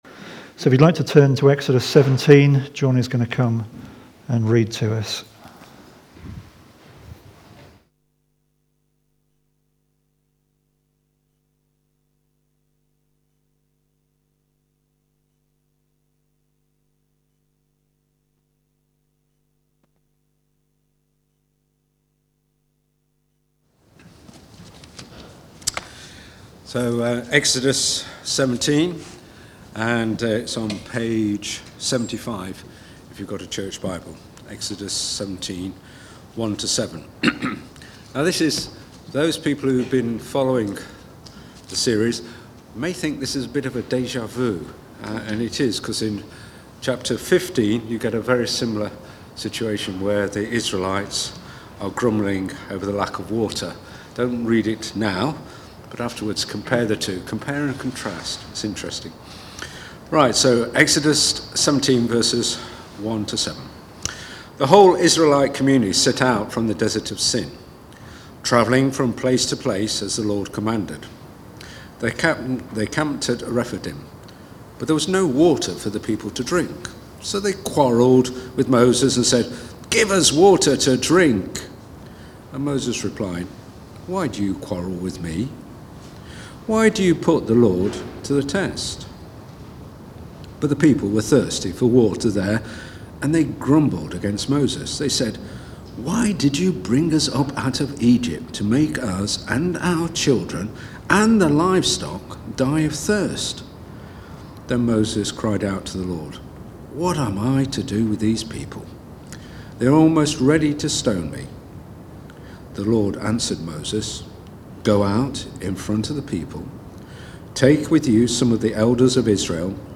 The Rock That is Struck (Exodus 17:1-7) from the series Wilderness Wanderings. Recorded at Woodstock Road Baptist Church on 03 April 2022.